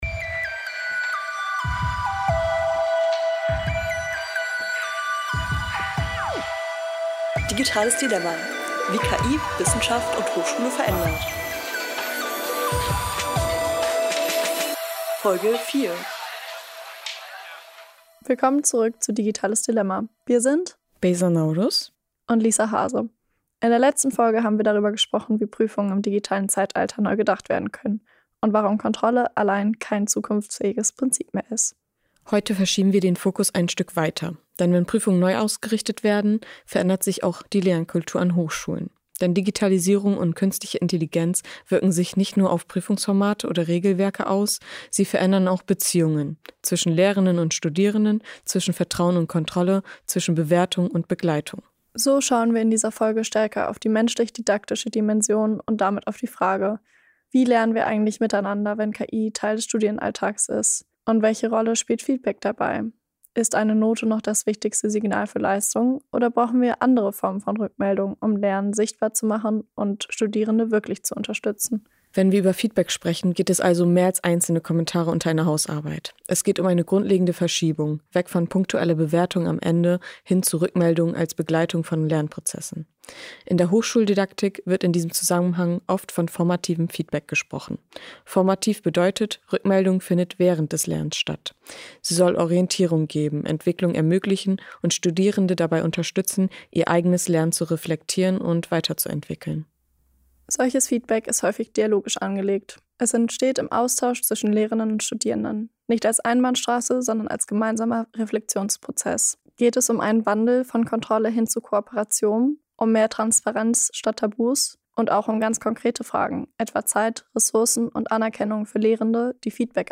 Gemeinsam mit einer Expertin und einer Studentin diskutieren wir, welche Rolle Portfolios, dialogisches Feedback und metakognitive Kompetenzen spielen können und fragen: Wie kann eine Hochschulkultur aussehen, in der Lernen als gemeinsamer Prozess verstanden wird?